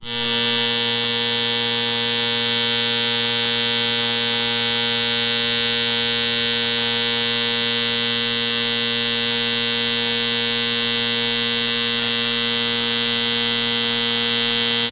Alarm clock with ring tones - Eğitim Materyalleri - Slaytyerim Slaytlar